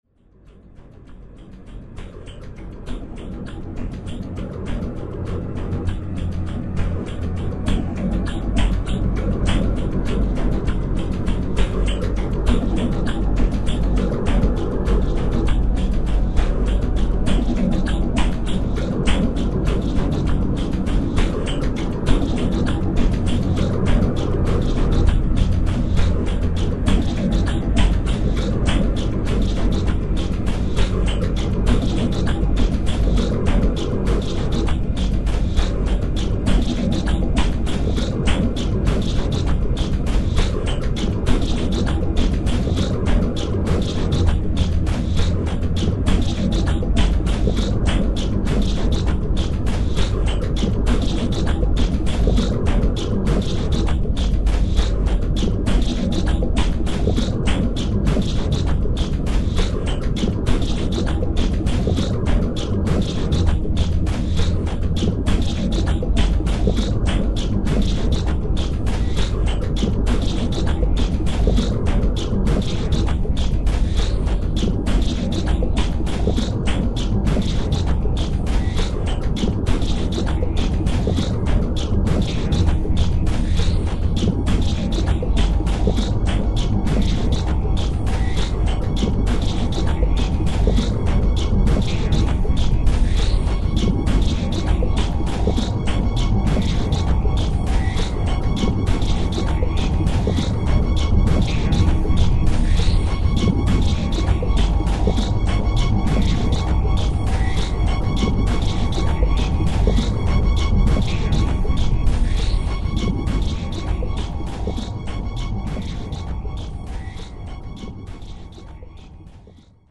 ambient, drone, and textures.